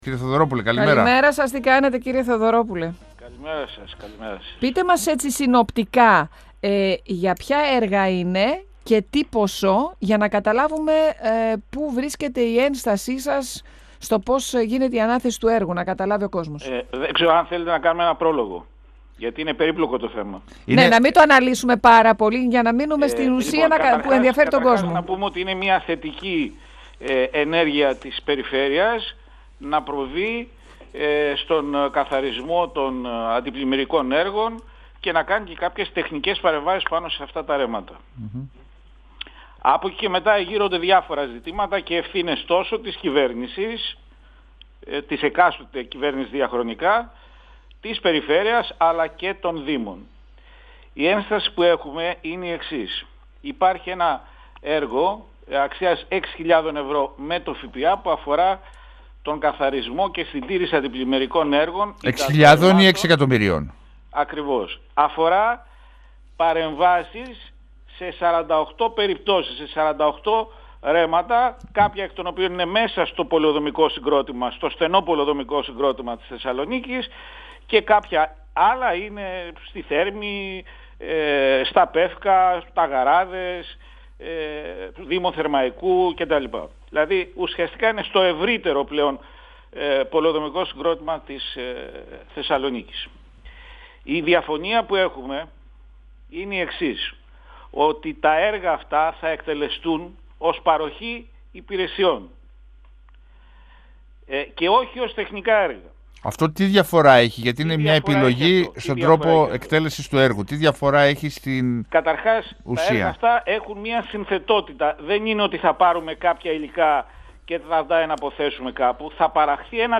Ο περιφερειακός σύμβουλος Κεντρικής Μακεδονίας Γιώργος Θεοδωρόπουλος στον 102 fm της ΕΡΤ3